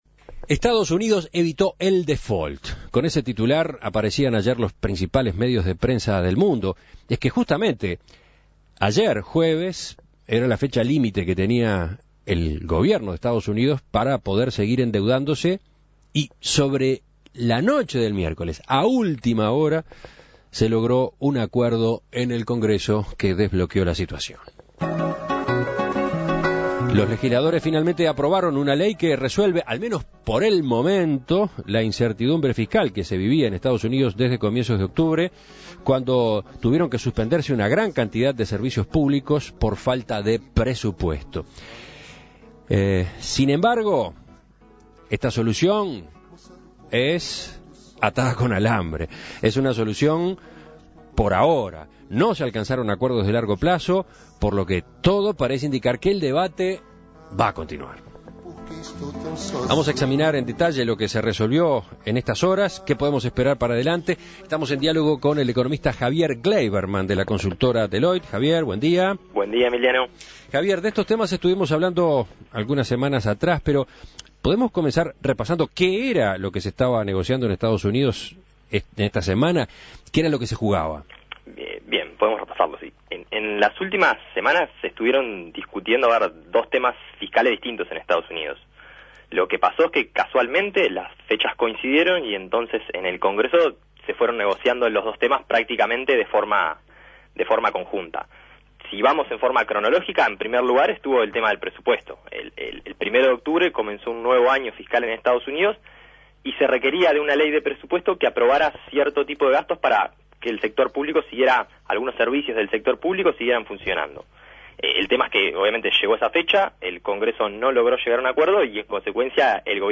Análisis Económico La perspectiva fiscal en Estados Unidos luego del acuerdo "de último minuto" para evitar el default